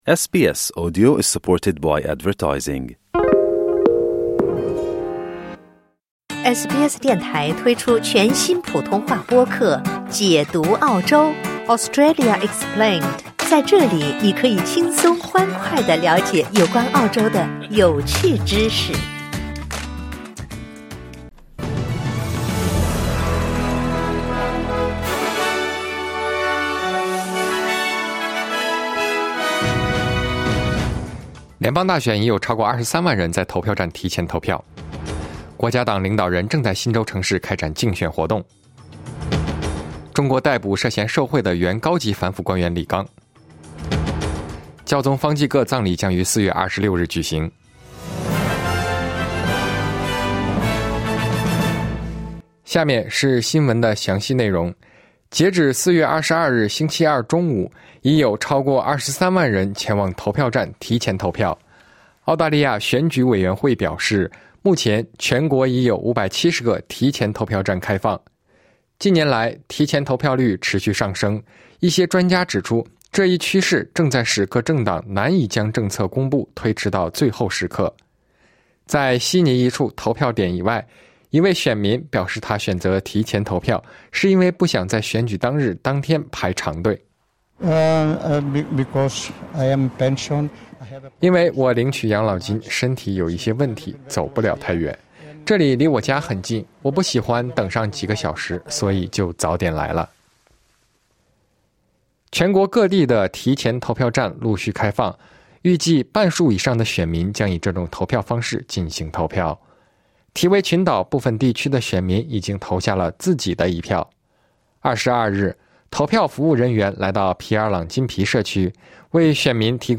SBS早新闻（2025年4月23日）
SBS 新闻快报